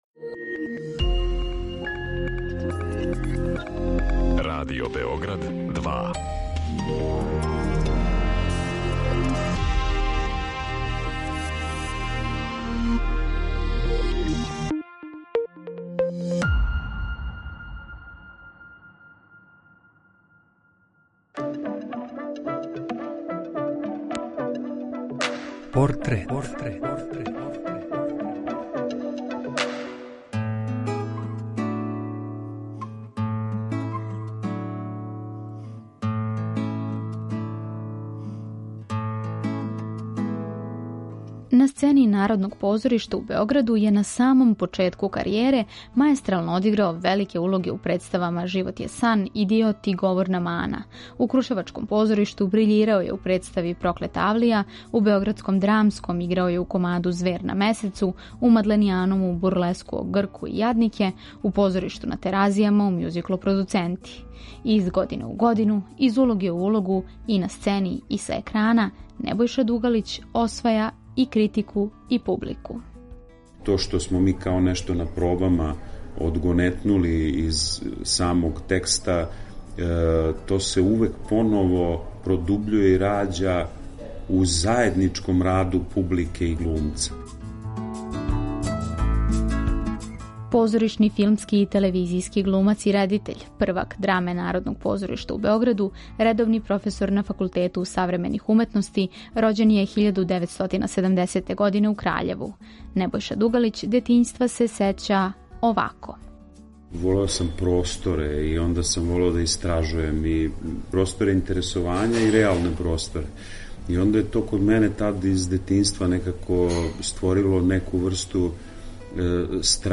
Приче о ствараоцима, њиховим животима и делима испричане у новом креативном концепту, суптилним радиофонским ткањем сачињеним од: интервјуа, изјава, анкета и документраног материјала.
Чућете шта је Небојша Дугалић рекао за Портрет о свом детињству, каријери, опредељености између позоришта и телевизије... А о њему ће говорити његови пријатељи и сарадници - глумац Гордан Кичић и позоришни редитељ Јагош Марковић.